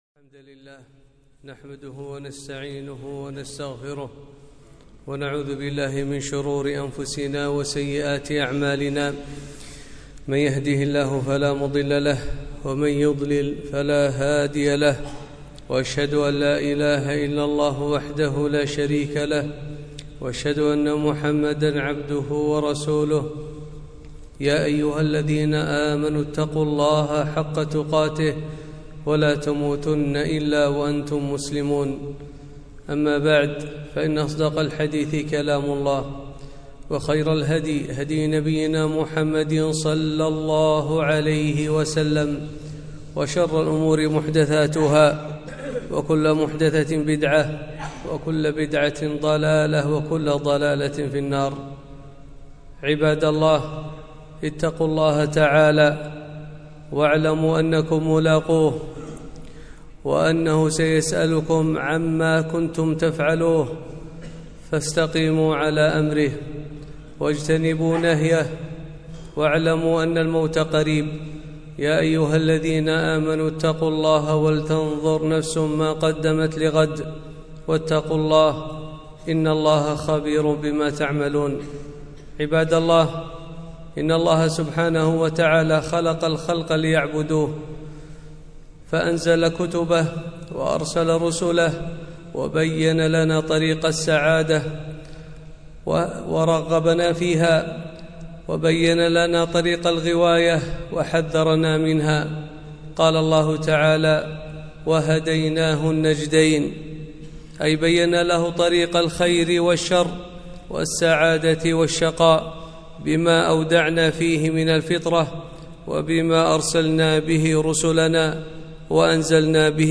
خطبة - (وهديناه النجدين)